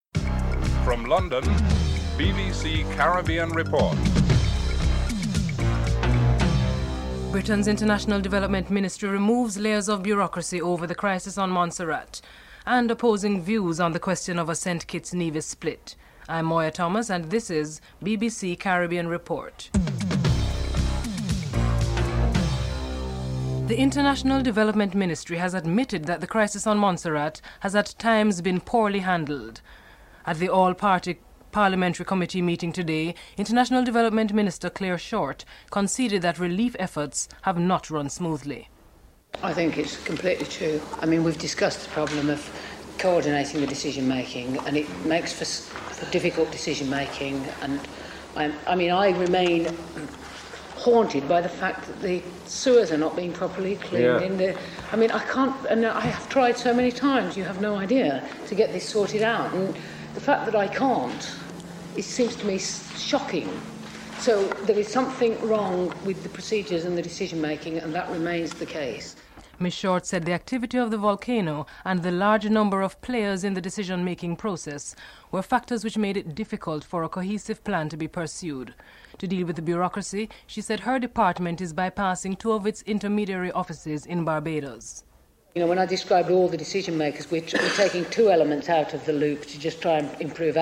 Foreign Office Minister Clare Short is interviewed
Nevisian Premier Vance Amory and Prime Minister Denzil Douglas are interviewed (08:05-12:17)